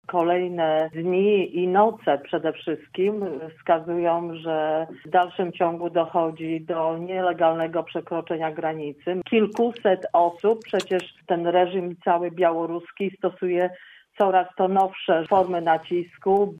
Bożena Ronowicz z Prawa i Sprawiedliwości, mówiła, że w jej ocenie nadal poziom zagrożenia jest duży: